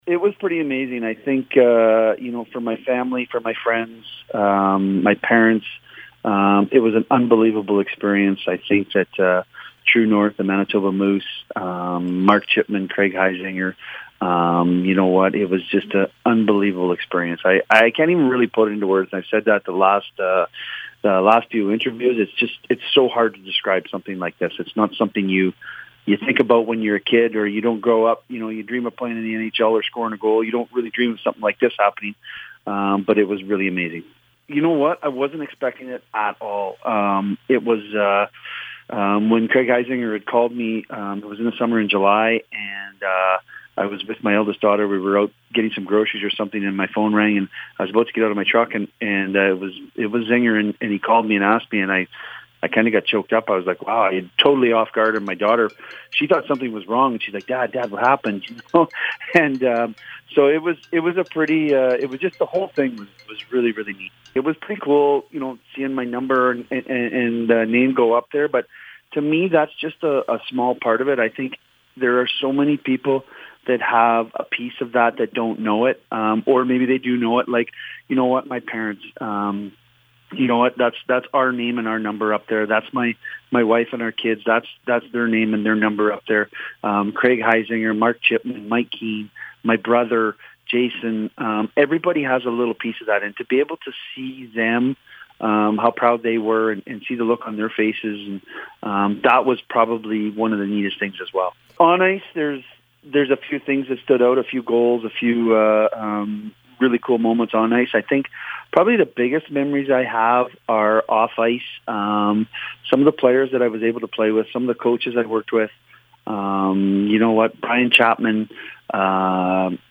In an earlier interview with CKDR News